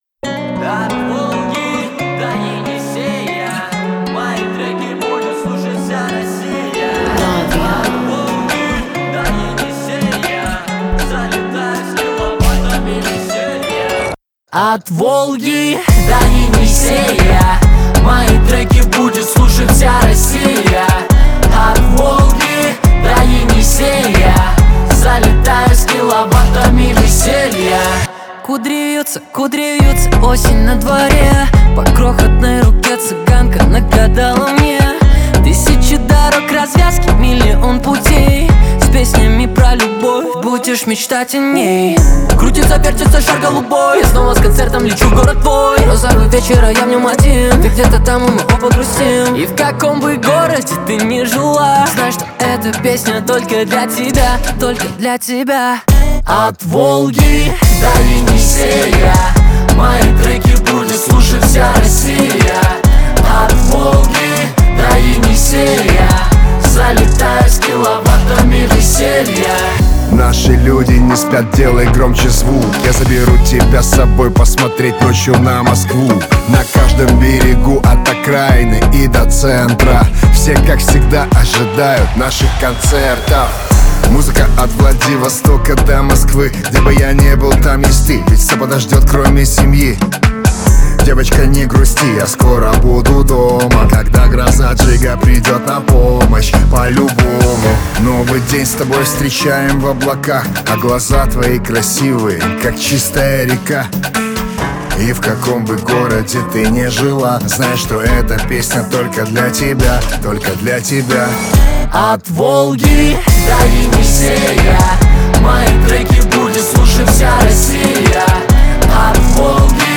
Качество: 320 kbps, stereo
Русские поп песни, Рэп